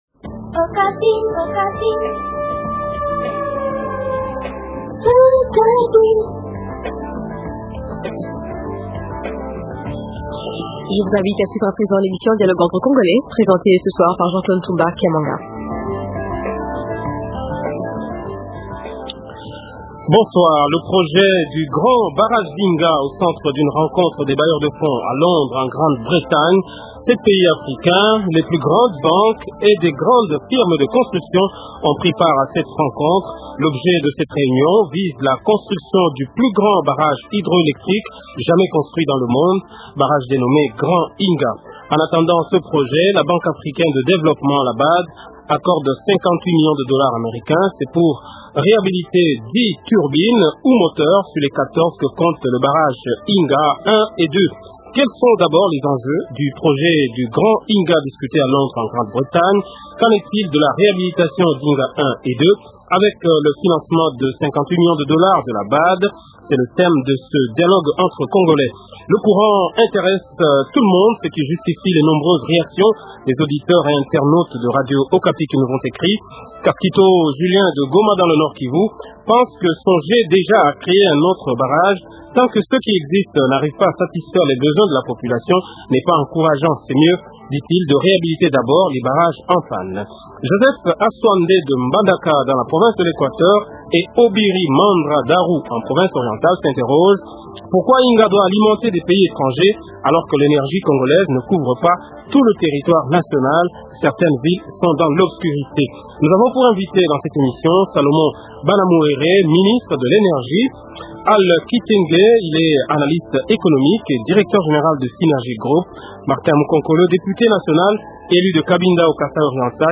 salomon Banamuhere, Ministre de l’énergie
Martin Mukonkole, député national ODR rn